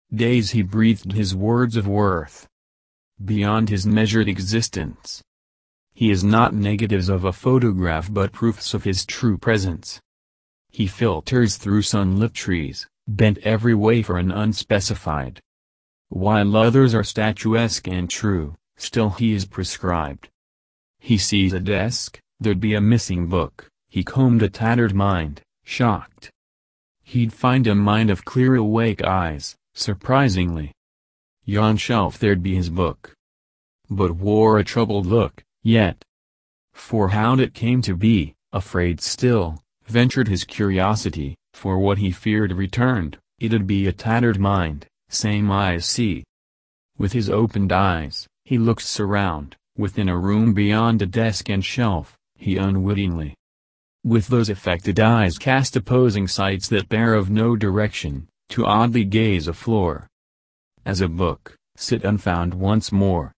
nice music